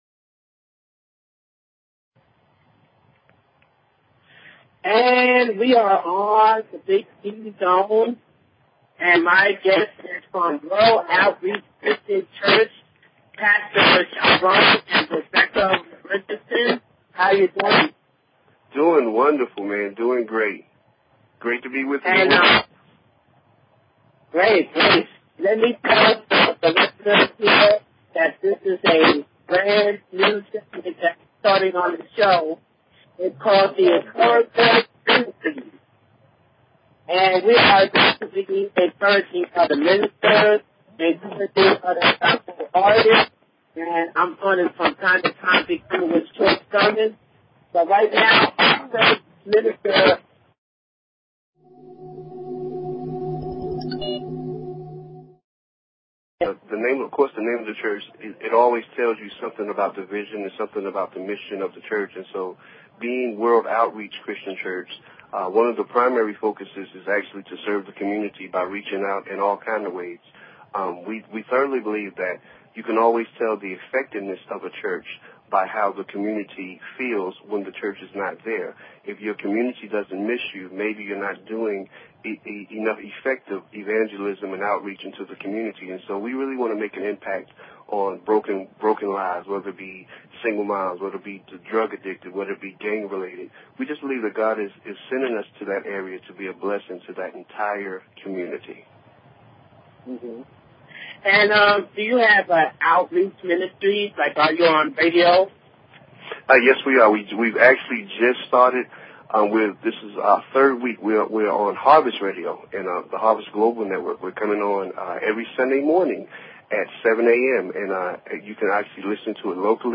Talk Show Episode
The show will feature artists from R&B, RA, HIP HOPGOSPEL, POETRY, ROCK, AND MAY BE MORE!